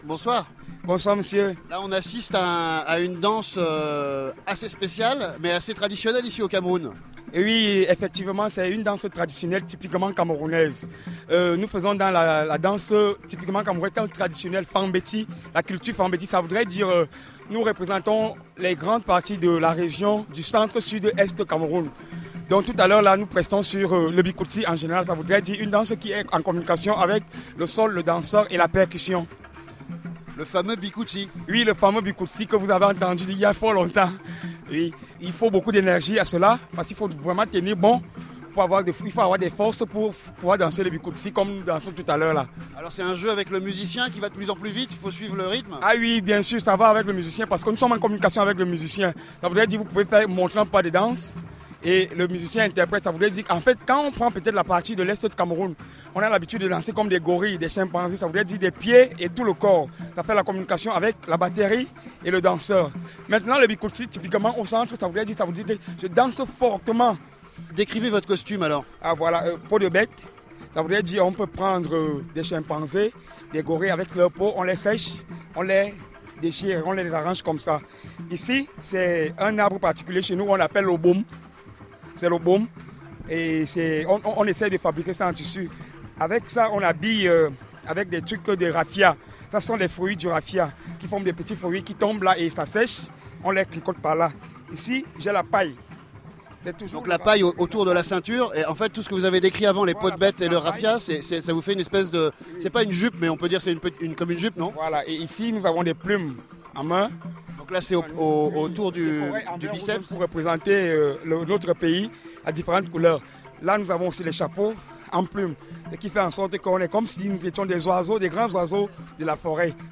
Yaoundé (Cameroun)
Bikutsis-3.mp3